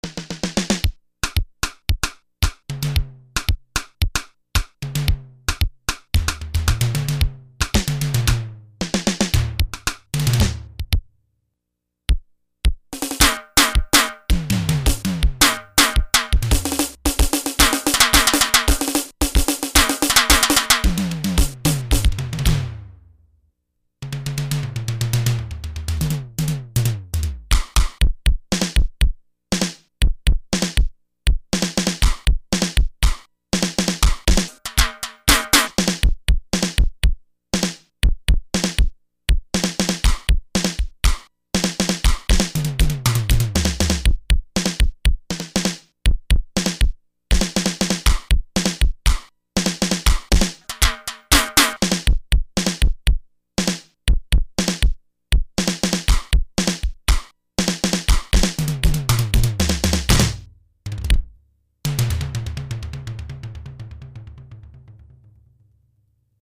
1_Simmons-SDS-9-demo-sequence.mp3